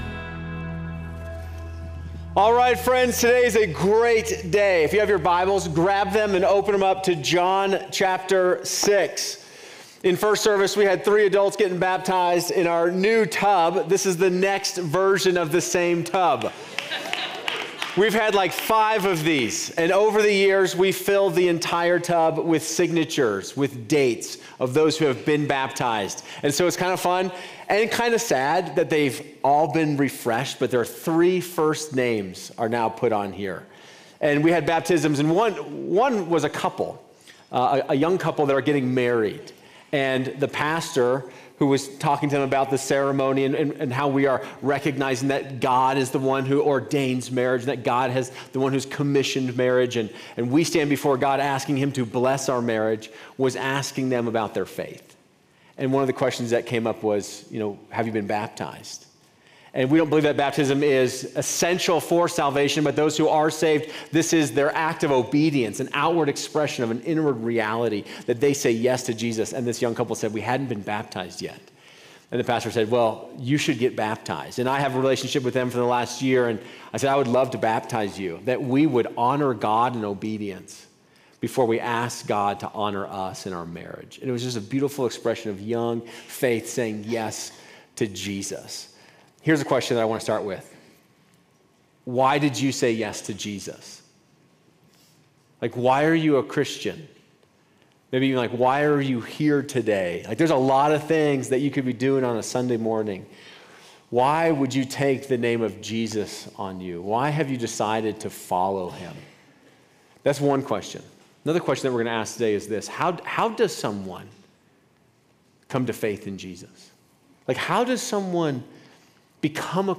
In this thought-provoking sermon, we delve into John chapter 6 to explore the profound questions of faith: Why do we follow Jesus?